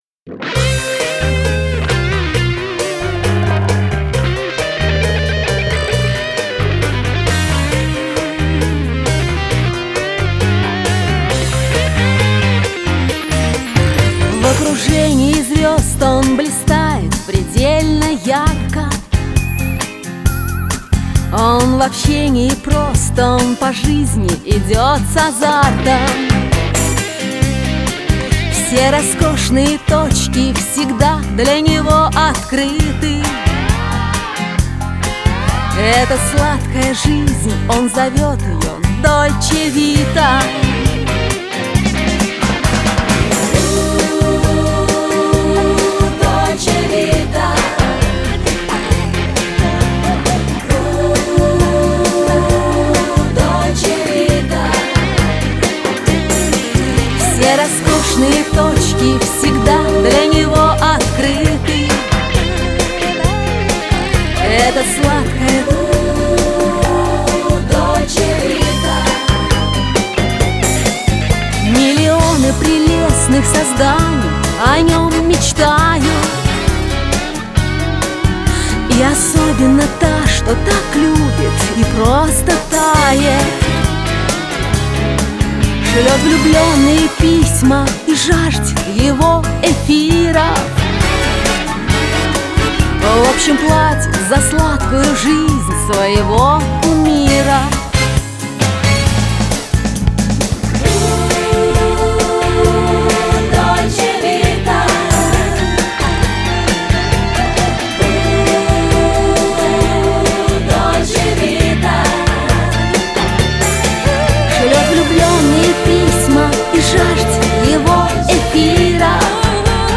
Мне понравилась песенка.
Действительно, приятный голос.